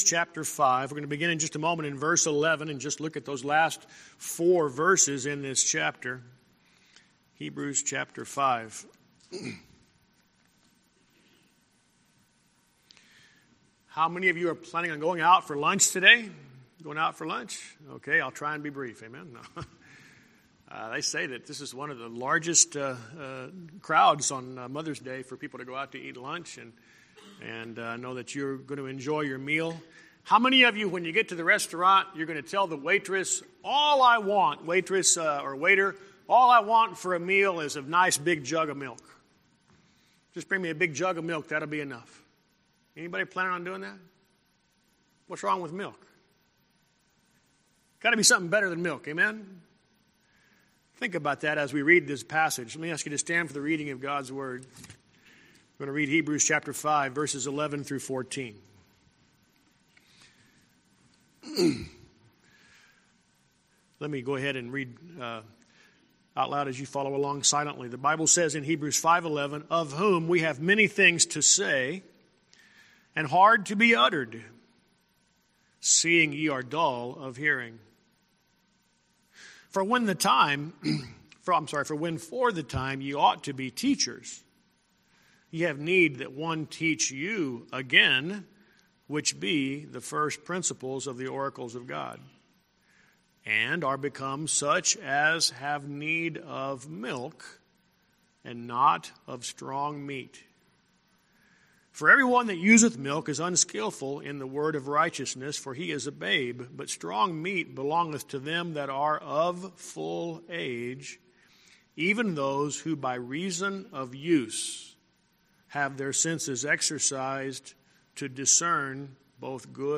Calendar Sermons And Lessons Sermon Recordings Sunday School Recordings Lesson Handouts Guest Speakers Christian School Map Contact Us Login The Most Important Minute Youtube Channel Subscribe to Email Milk or Meat?